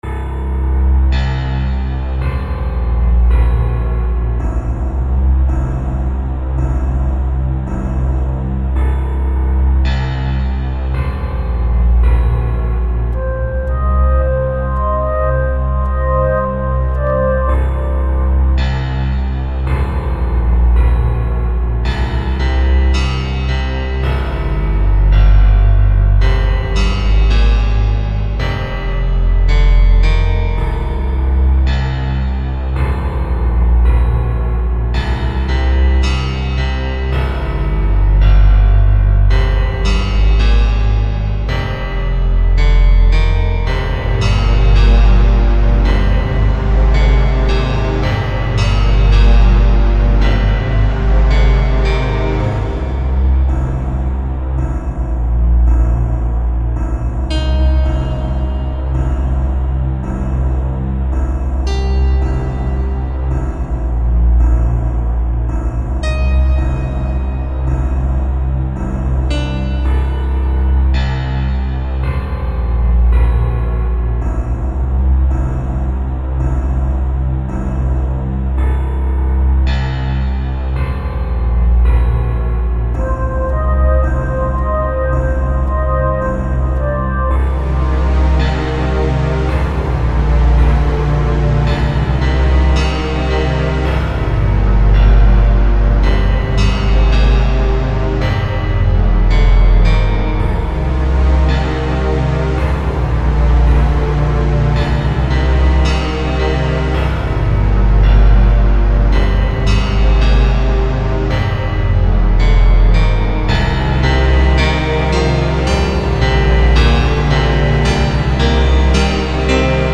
I'm glad I still have the low quality mp3s from those days.